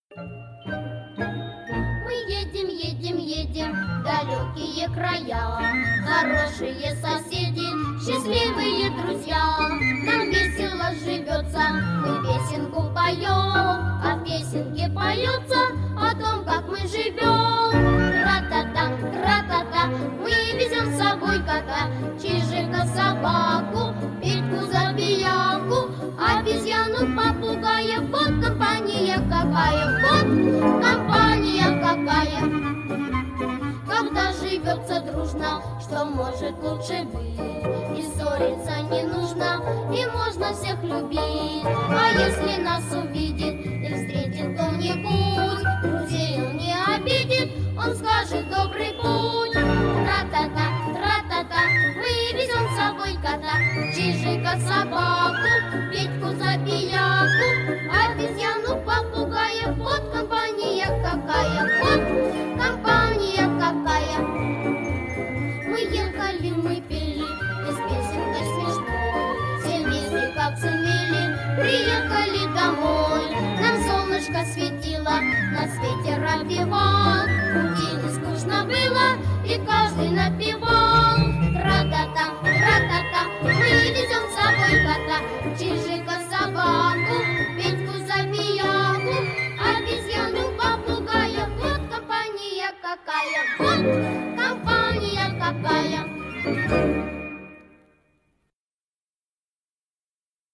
песня из мультфильма.
самая добрая, яркая и динамичная песенка для малышей.